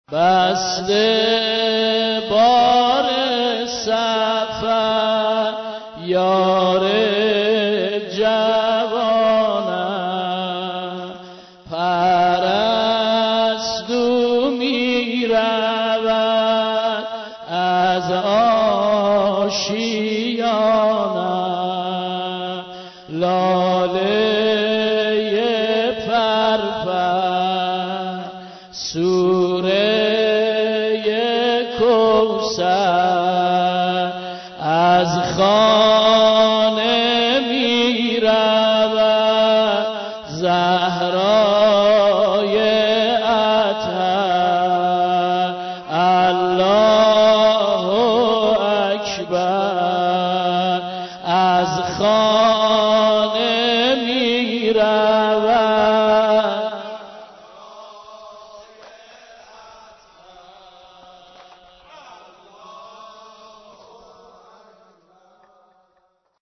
نوحه